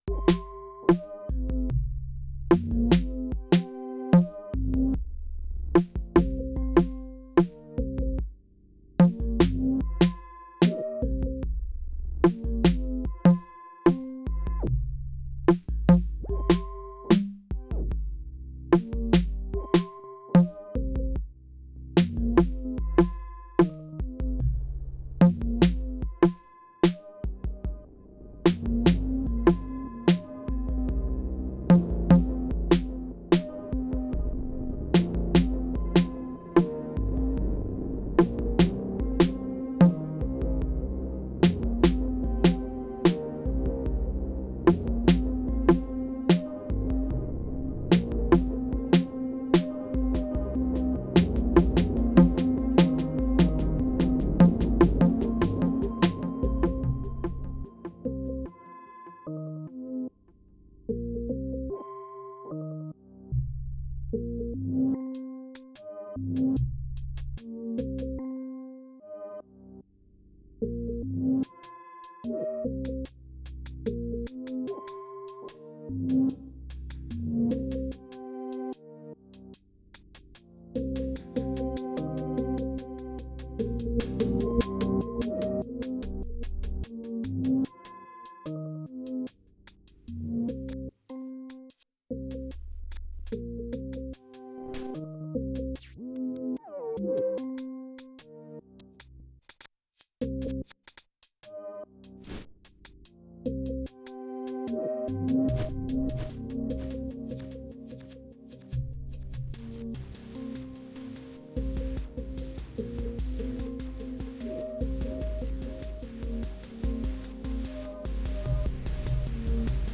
Here is a little track I did with it. 4 sounds, all using the random LFO from above. i’m ‘performing’ it with the ct-all and reload pattern and muting.
One observation… it seems less random when it is music NOT slot numbers.